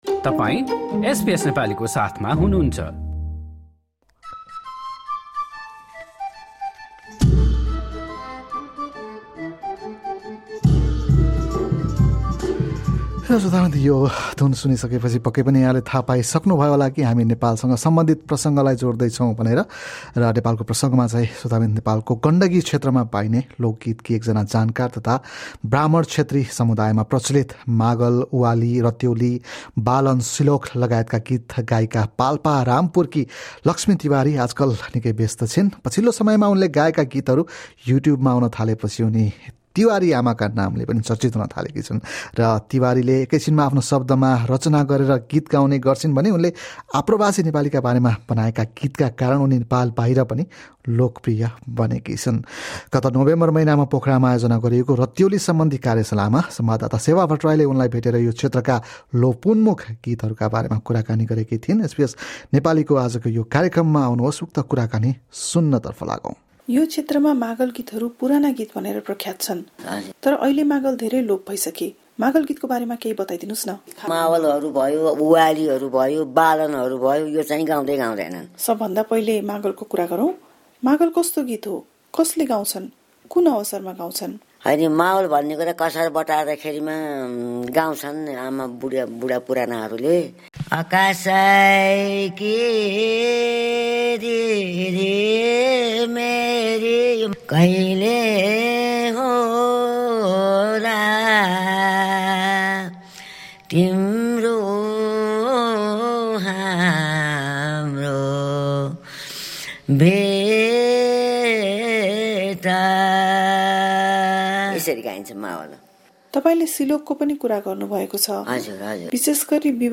Her songs reflecting the lives of Nepali migrants have also resonated with audiences beyond Nepal. During a Ratyauli workshop in Pokhara last November